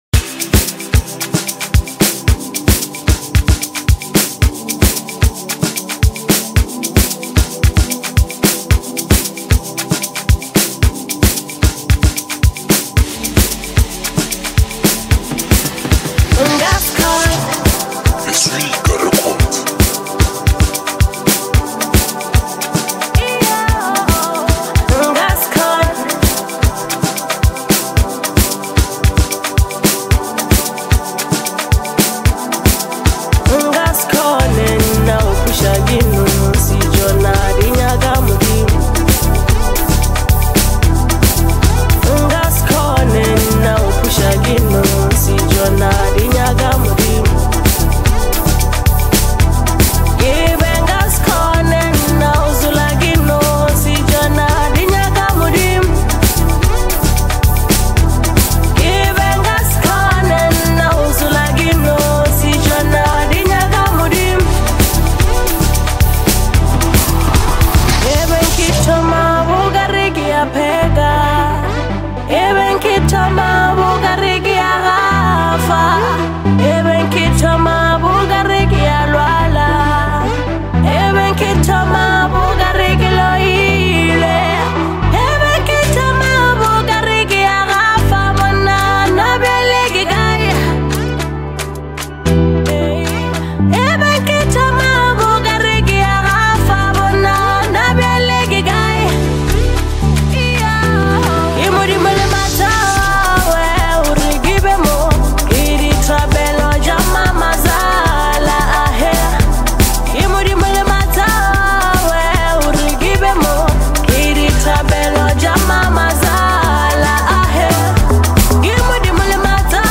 rich vocals, filled with emotion and intensity